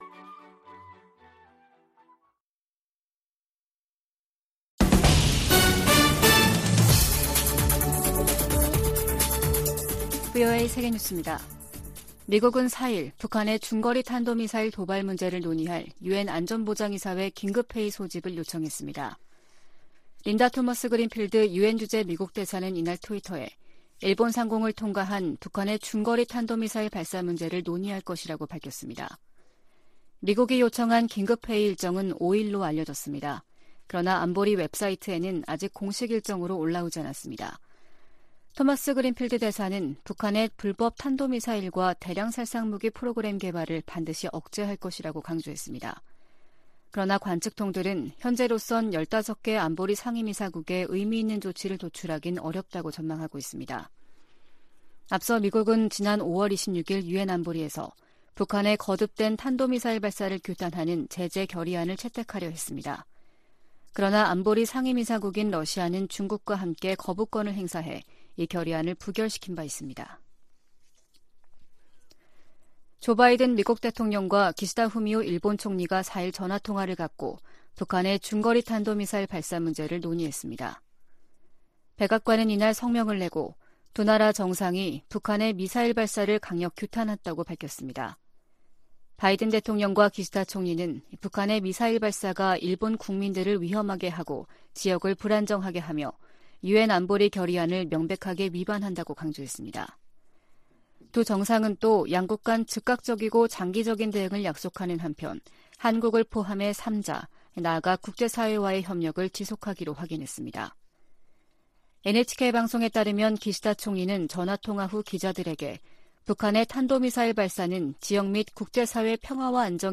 VOA 한국어 아침 뉴스 프로그램 '워싱턴 뉴스 광장' 2022년 10월 5일 방송입니다. 북한이 4일 일본열도를 넘어가는 중거리 탄도미사일(IRBM)을 발사했습니다.